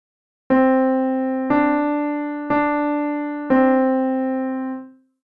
We will start with melodies using just two types of interval: Tones (a.k.a. major seconds, whole steps) and Major Thirds.
The melodies will be short and slow.
Key: C Major, Starting Note: C